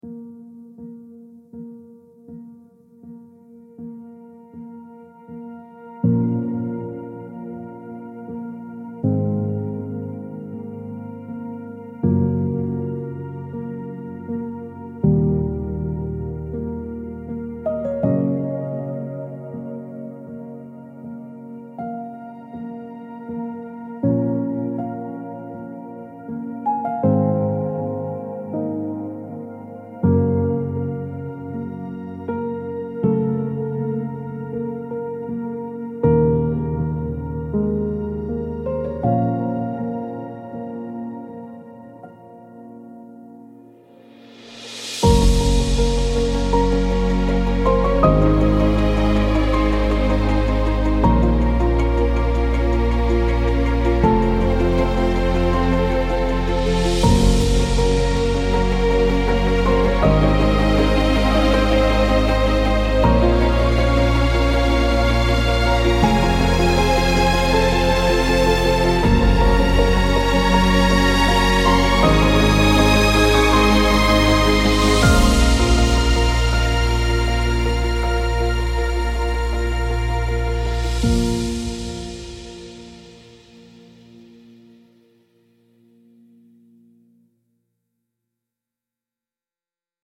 slow cinematic strings building to a hopeful crescendo with gentle piano